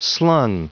Prononciation du mot slung en anglais (fichier audio)
Prononciation du mot : slung